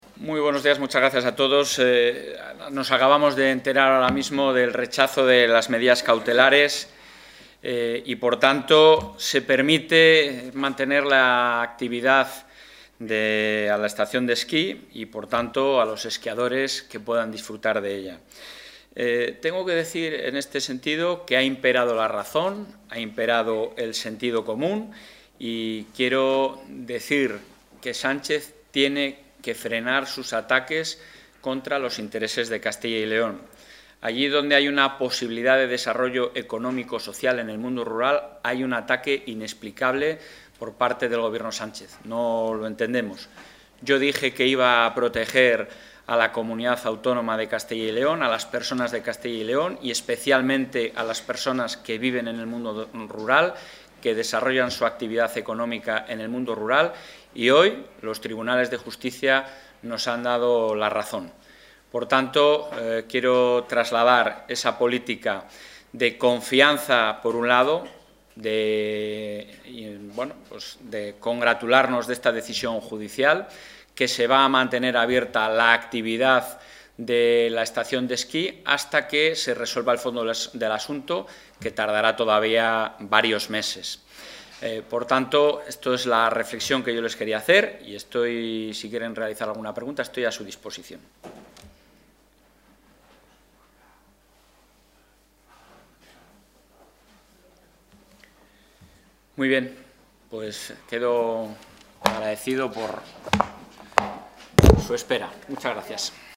Declaraciones sobre Navacerrada.